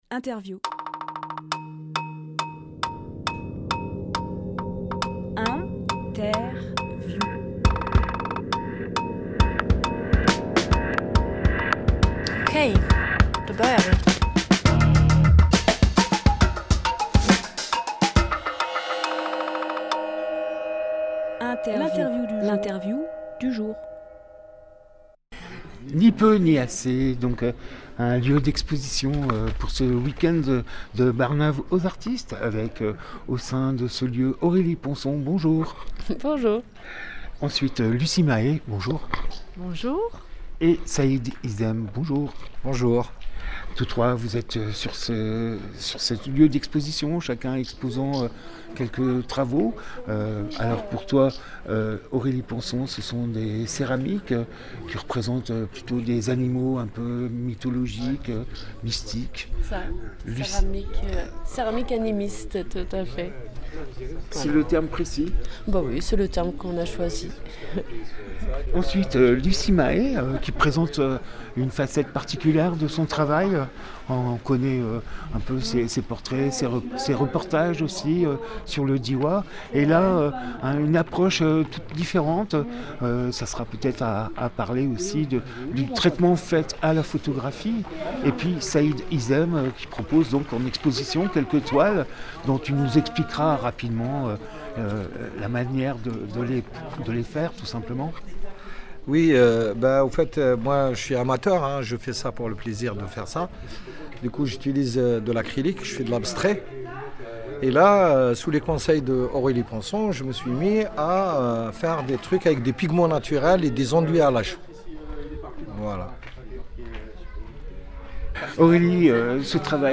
Emission - Interview Barnave aux artistes : Ni peu Ni assez Publié le 2 août 2023 Partager sur… Télécharger en MP3 Barnave Aux Artistes, première édition d’un évènement artistique pluridisciplinaire à l’échelle du village de Barnave.